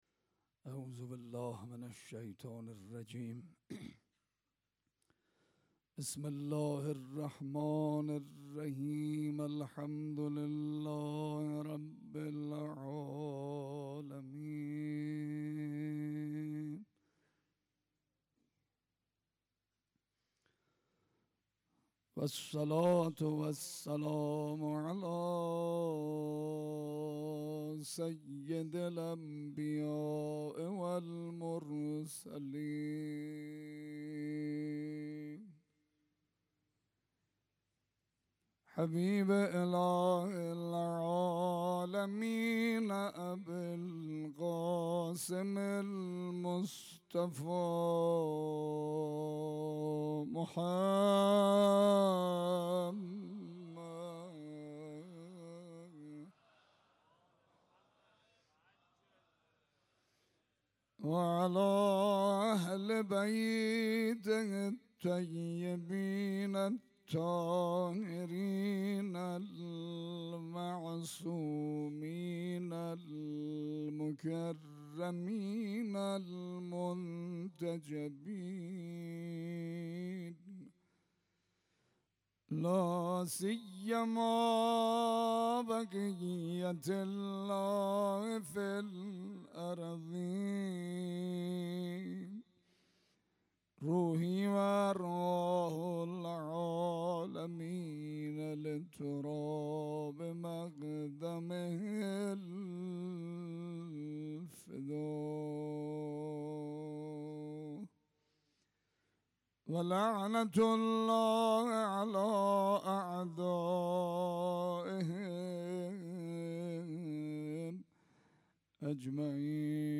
روضه روز هشتم محرم